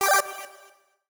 UIClick_Retro Delay 02.wav